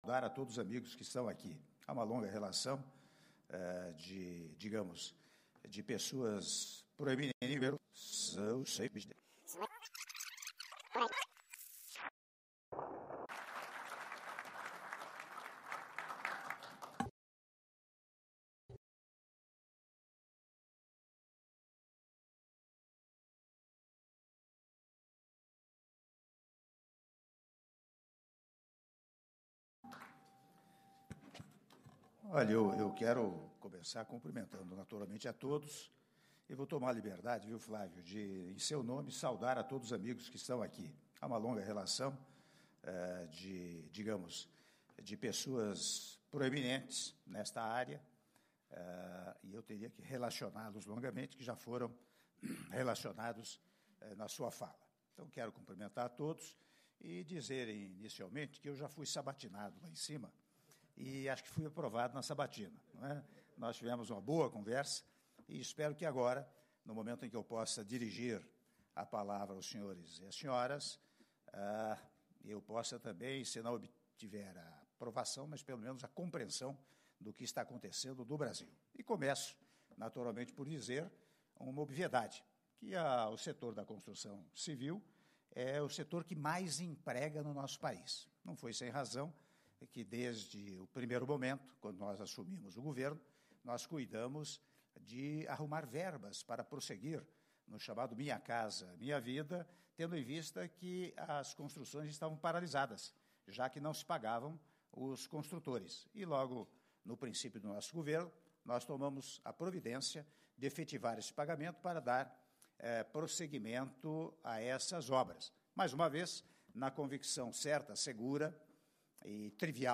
Áudio do Discurso do Presidente da República, Michel Temer, durante Encontro com empresários do setor imobiliário na sede do SECOVI-SP - São Paulo/SP (04min45s)